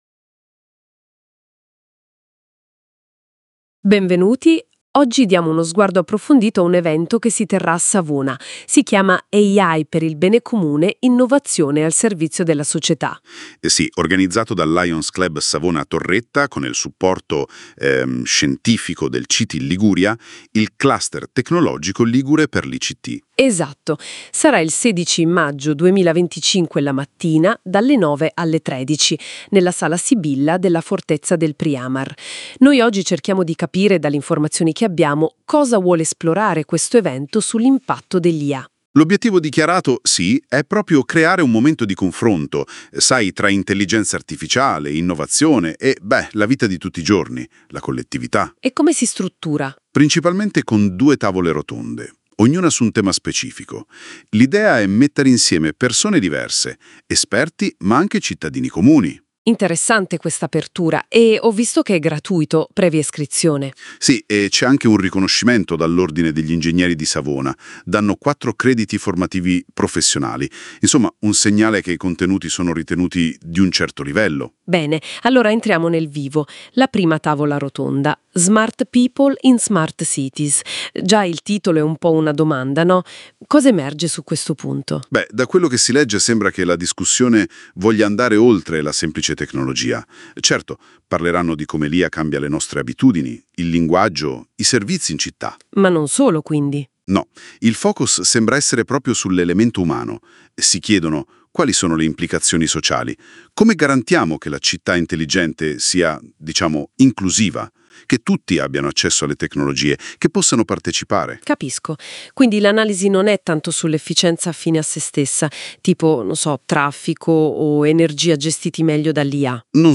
Nella magnifica cornice della Sala Sibilla del Priamar, si è tenuto l’evento “AI per il Bene Comune – Innovazione al servizio della società”, promosso dal Lions Club Savona Torretta in collaborazione con il Distretto 108Ia3, CTI Liguria e UCID Savona, con il patrocinio del Comune di Savona, dell’associazione Donne 4.0, di Save the Woman e dell’Ordine degli Ingegneri della Provincia di Savona.